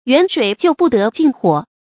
远水救不得近火 yuǎn shuǐ jiù bù dé jìn huǒ
远水救不得近火发音